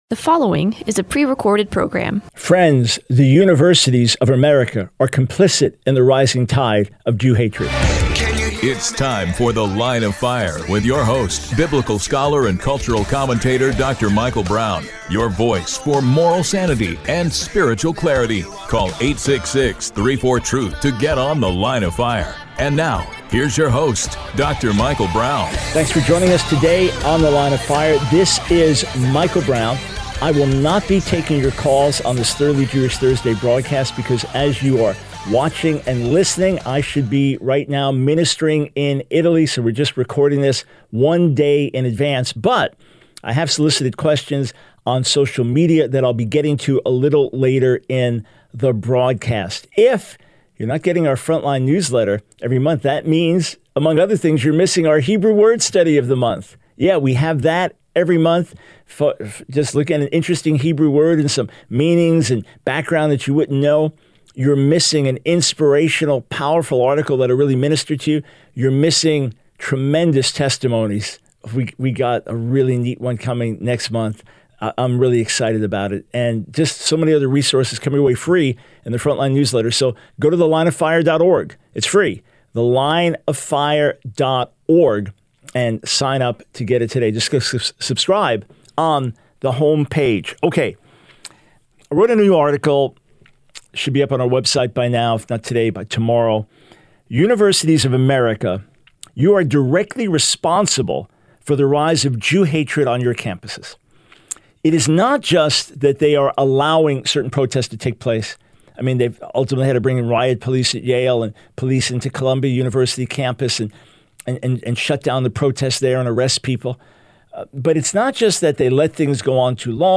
The Line of Fire Radio Broadcast for 04/25/24.